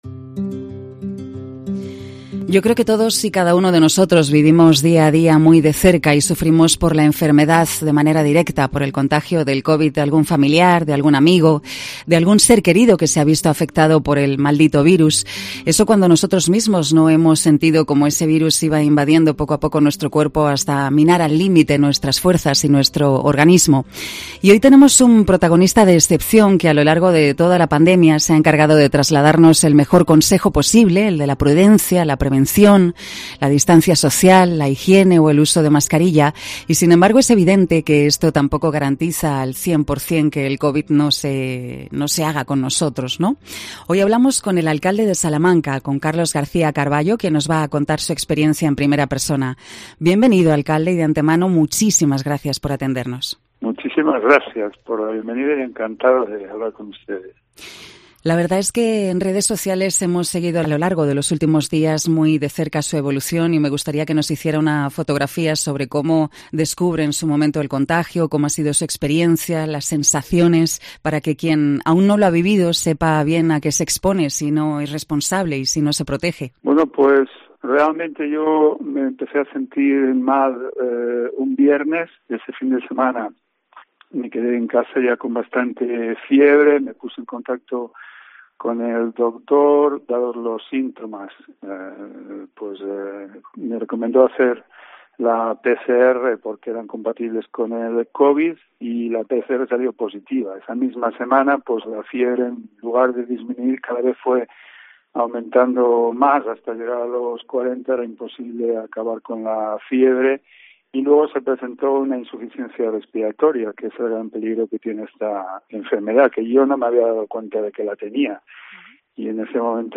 AUDIO: El alcalde Carlos García Carbayo nos cuenta su experiencia como enfermo de Covid.